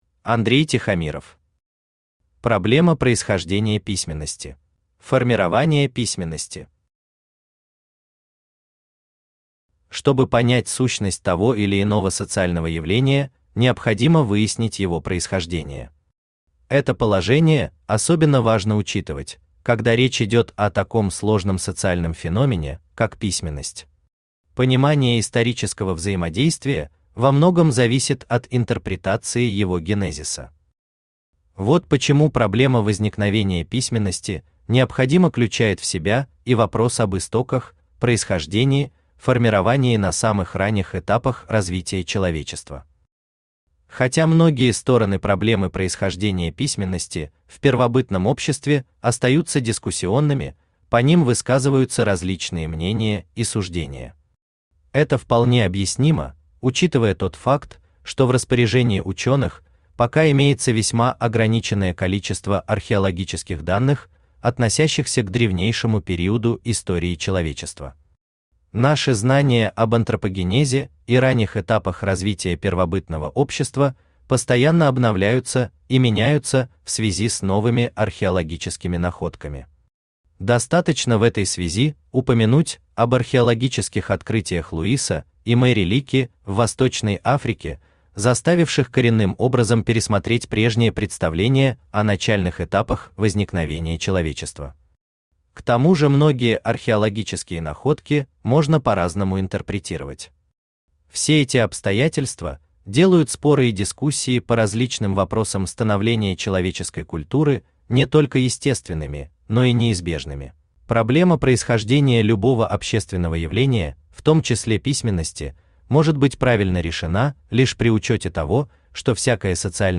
Аудиокнига Проблема происхождения письменности | Библиотека аудиокниг